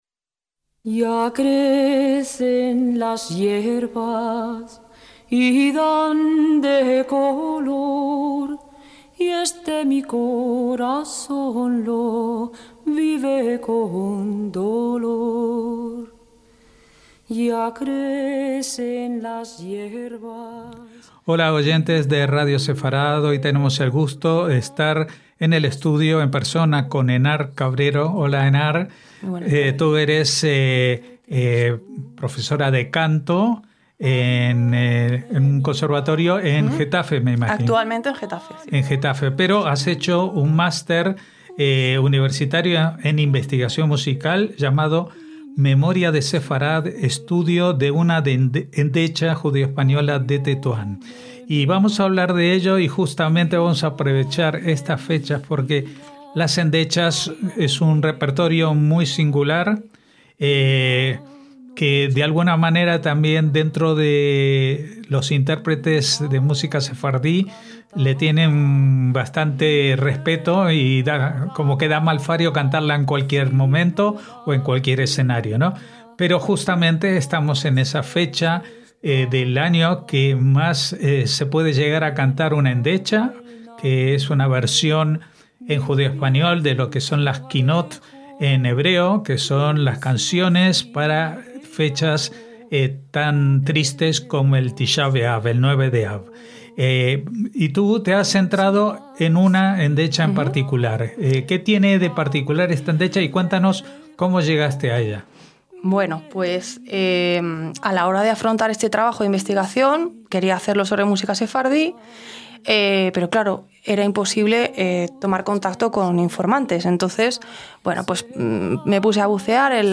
LA ENTREVISTA - La endecha es un género especial del repertorio de la música sefardí en judeoespañol, una canción de duelo que no suele interpretarse en cualquier ocasión, pero sí en días de duelo como el 9 del mes de Av, en el que se conmemoran muchas desgracias acaecidas al pueblo judío.
Hablamos con ella de ello y nos regaló una interpretación musical propia a voz desnuda.